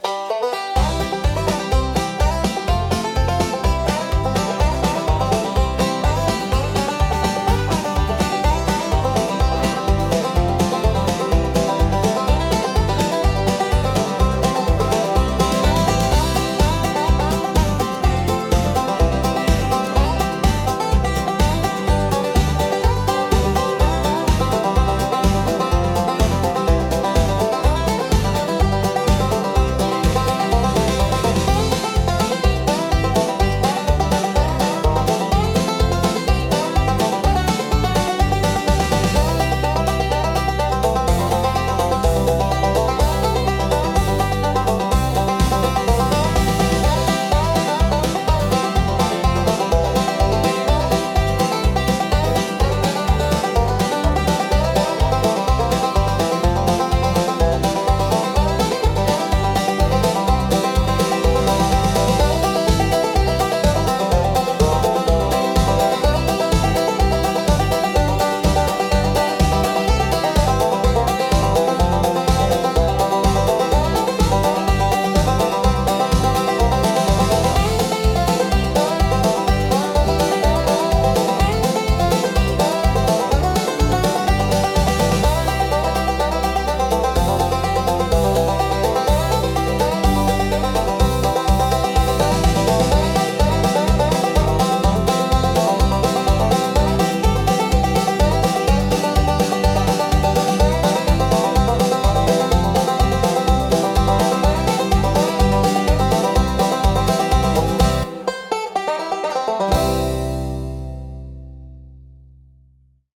カントリーやフォークの要素を感じさせるリズミカルな演奏が、陽気で温かみのある空気を作り出します。